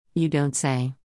（ユ　ドンセイ）
「ドントセイ（don't say）」にアクセントを置くのが発音のポイント。